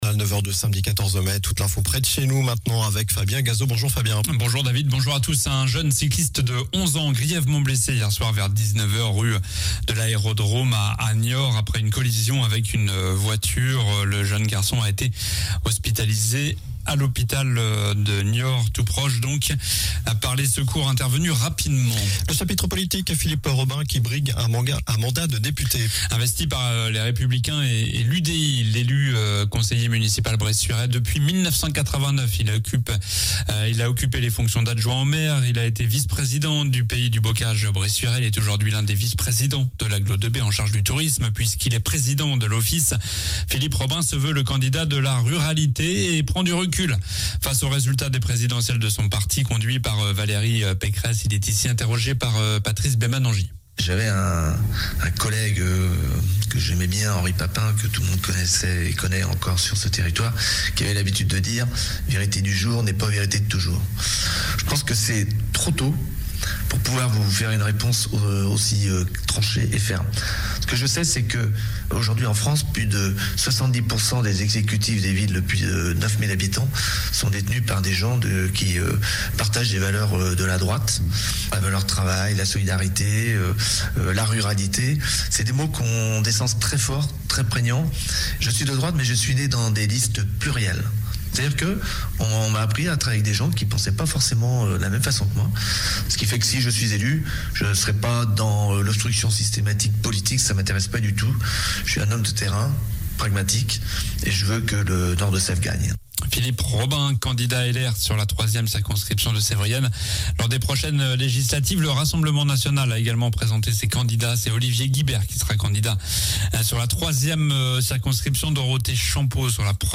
Journal du samedi 14 mai (matin)